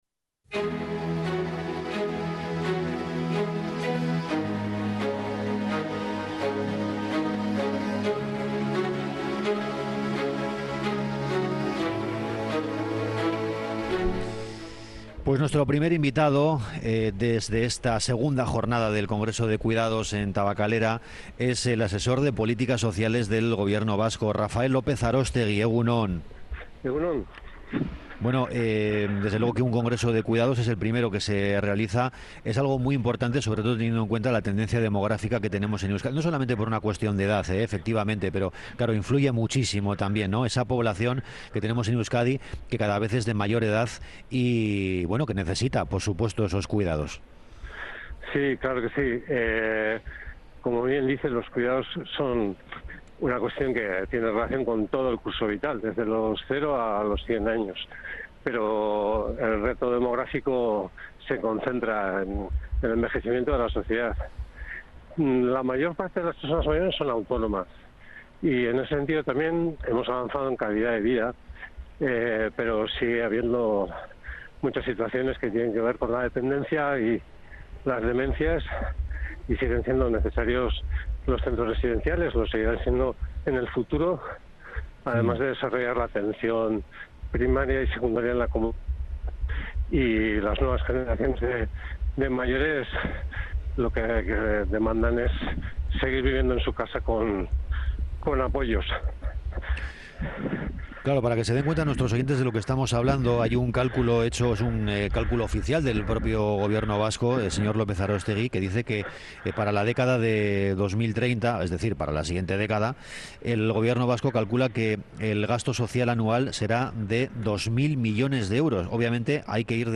Sobre todo esto se está hablando en el Congreso de los cuidados que se celebra desde ayer en Tabakalera y desde el que ONDA VASCA ha ofrecido una programación especial.